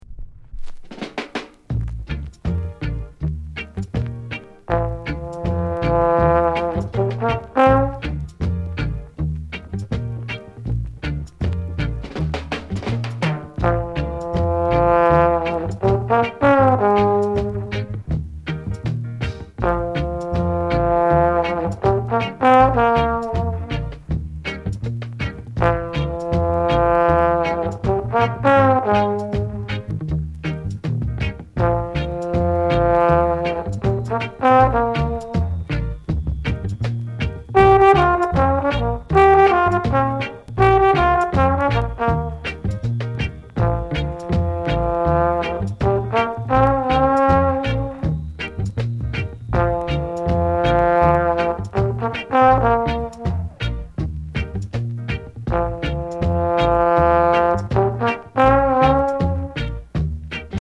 SLIGHT WARP ������ NICE TRONBONE INST